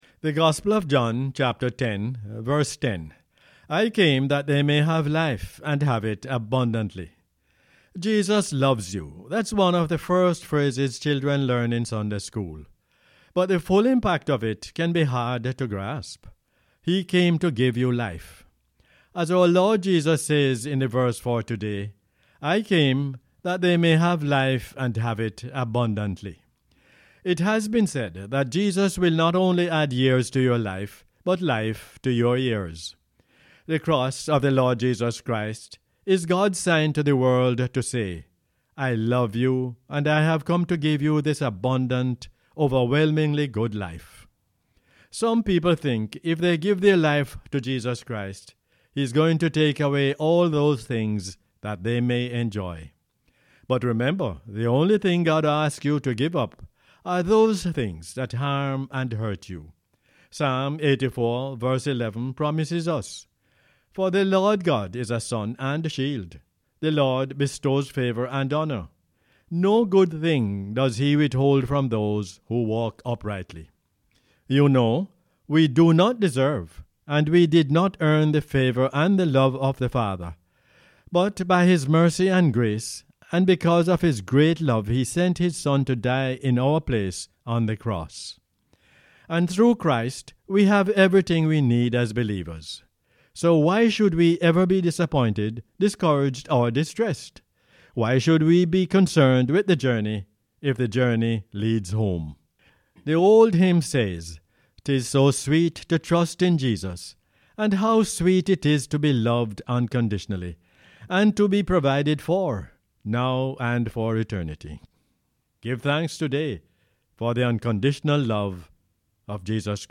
John 10:10 is the "Word For Jamaica" as aired on the radio on 8 November 2019.